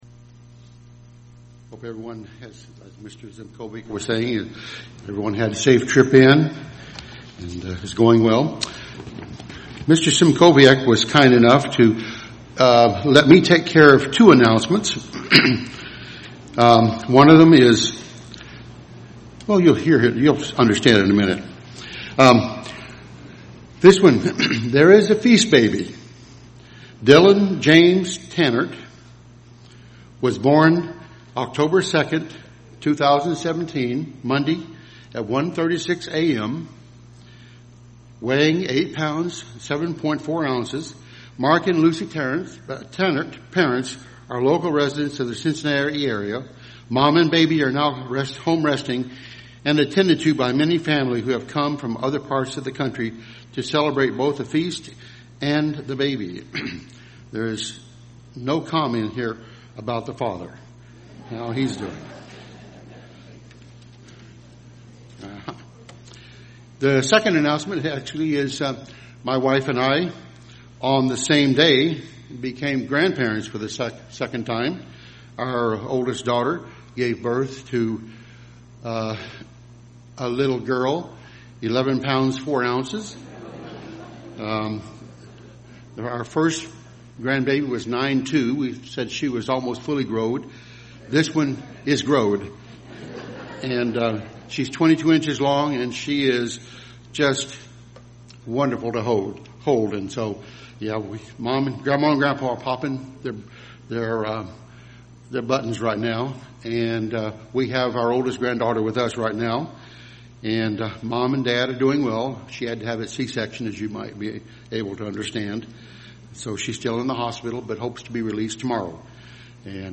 This sermon was given at the Cincinnati, Ohio 2017 Feast site.